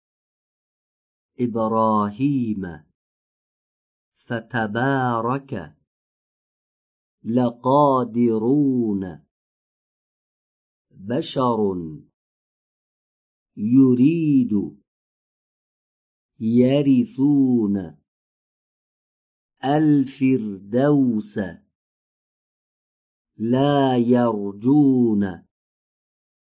📝تلفظ حرف ر
این حرف مانند «ر» در زبان فارسی از برخورد سر زبان به لثه دندان‌های پیشین بالا به تلفظ درمی‌آید.
👌هنگام تولید این حرف، هوای سازنده آن از بین ضرباتی که به طور سریع و سطحی از ناحیه سر زبان به لثه دندان‌های پیشین بالا وارد می‌شود عبور می‌کند و بدون آن که در مسیر آن انسداد و سایشی ایجاد شود به همراه ارتعاش تارهای صوتی به تلفظ درمی‌آید.